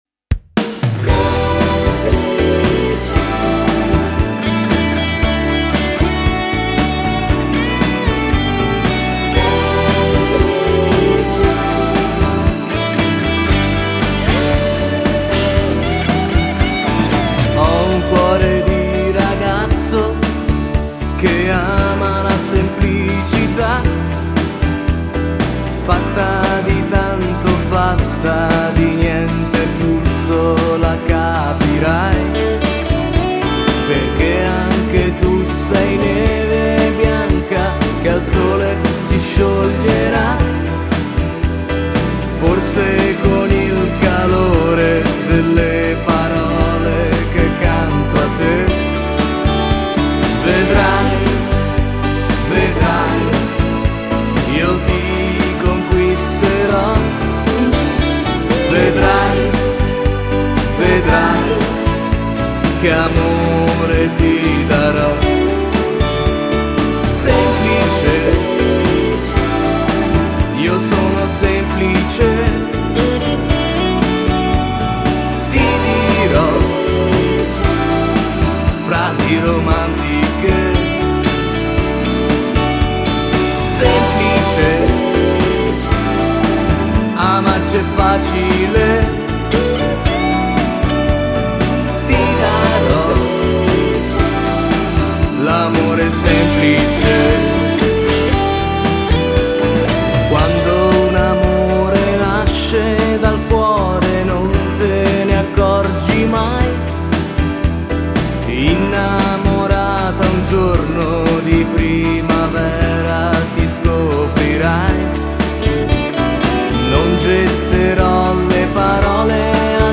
Genere: Moderato